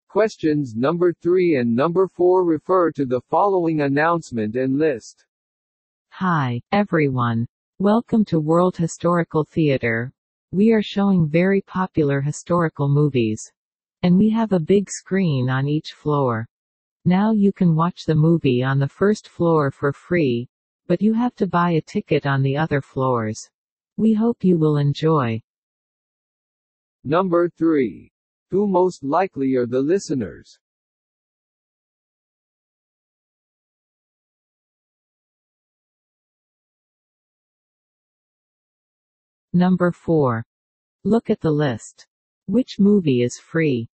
（音声は個人的にパソコンを使って作成したもので、本物の話者ではありません。